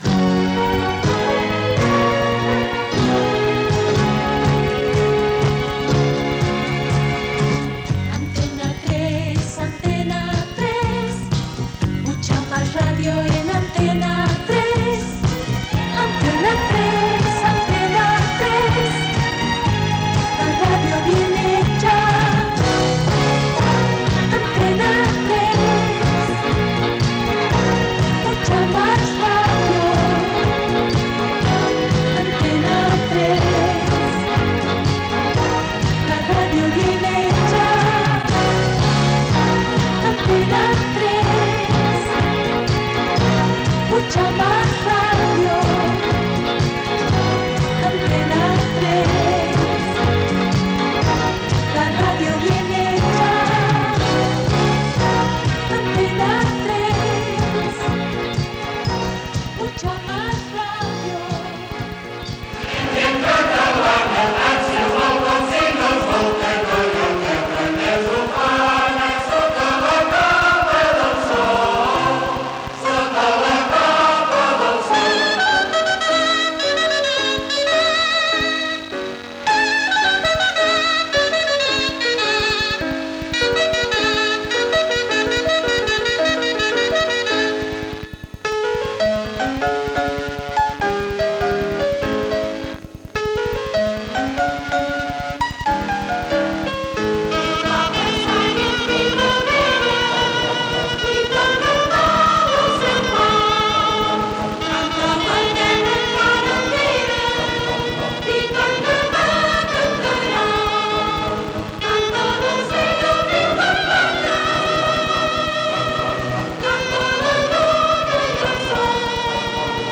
Informatiu
FM
Primer dia d'emissió d'Antena 3 de Barcelona des de Sonimag.